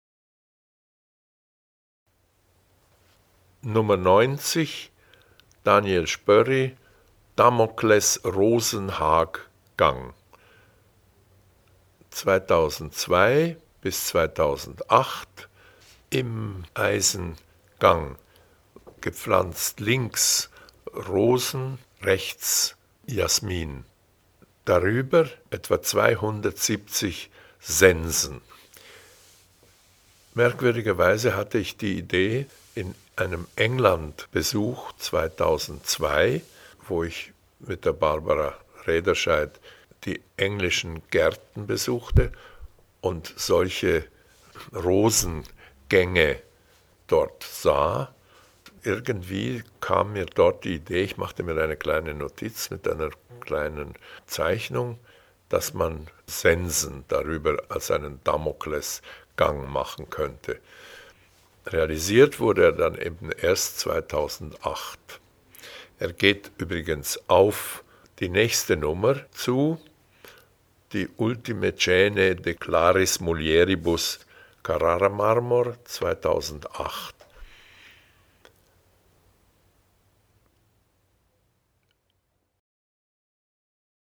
audioguide_90_spoerri_rosenhaag_giardino-daniel-spoerri.mp3